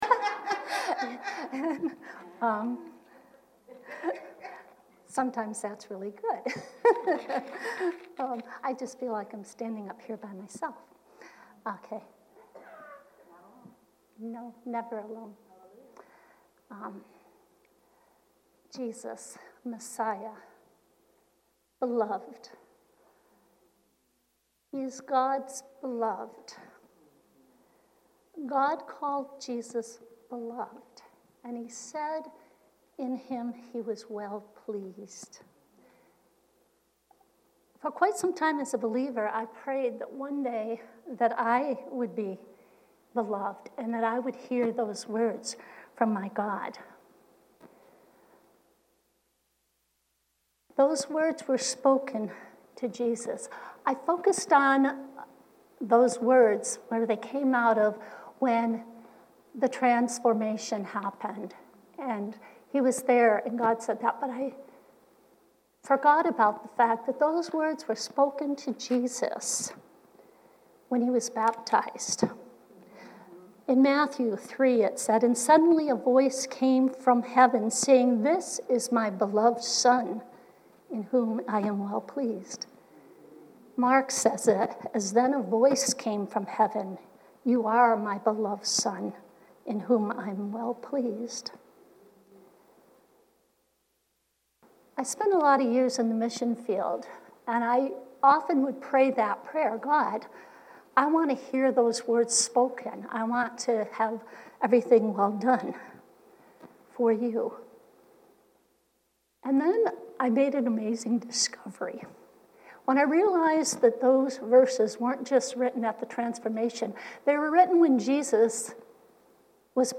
Women’s Fellowship Breakfast – “His Beloved”